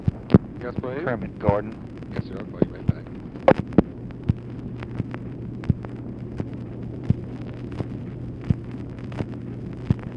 LBJ ASKS SIGNAL CORPS OPERATOR TO PLACE CALL TO KERMIT GORDON
Conversation with SIGNAL CORPS OPERATOR